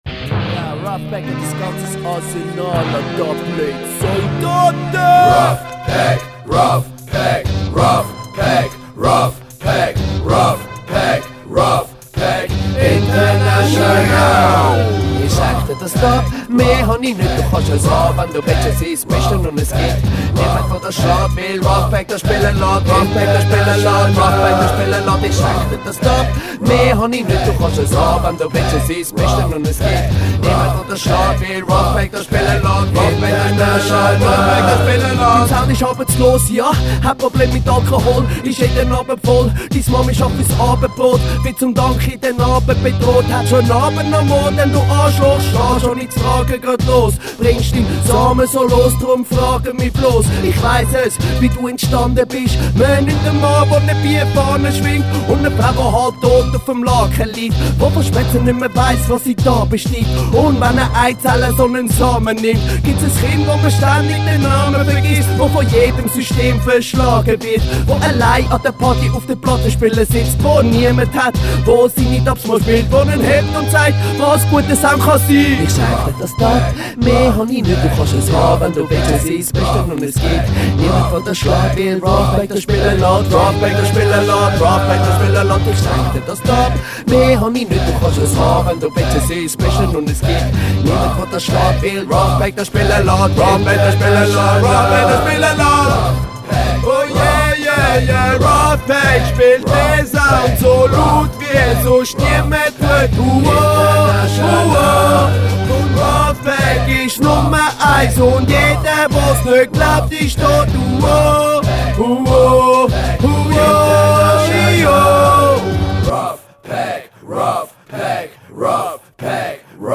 (Dubplate)